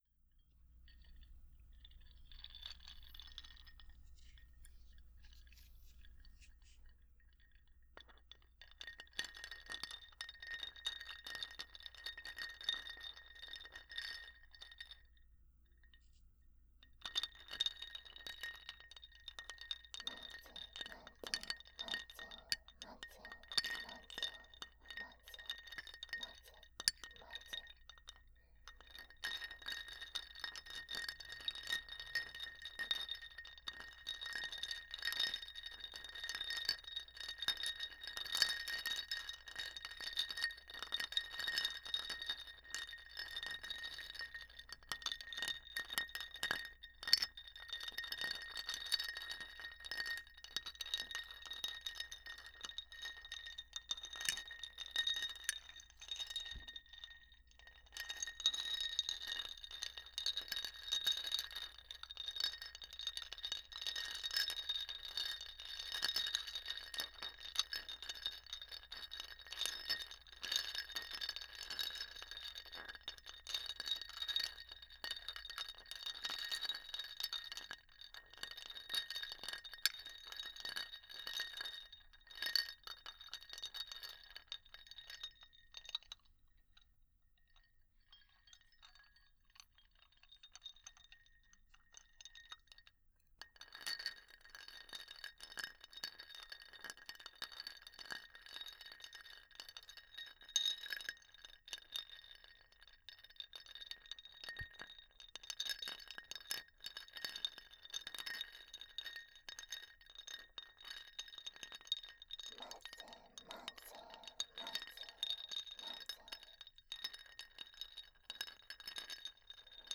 02.氷音パートのみ.wav